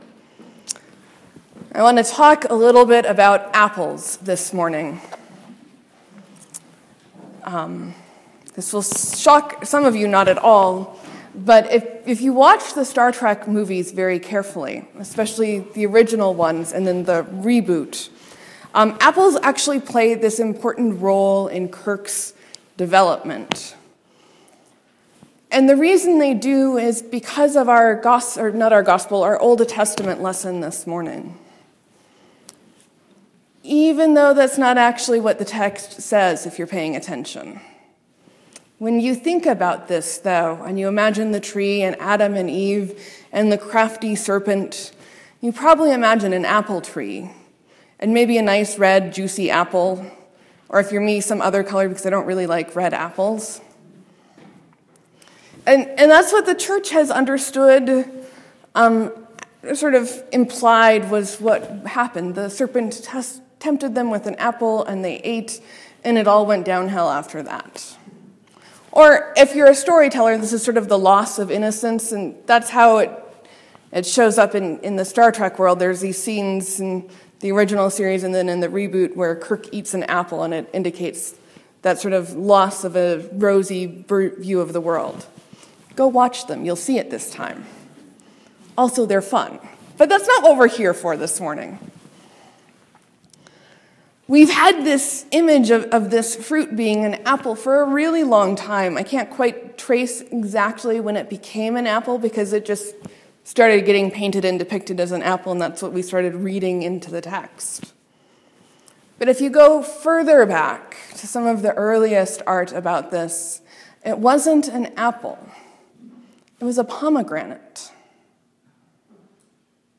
Sermon: What part of resisting temptation is having a community know and remind us of who we are?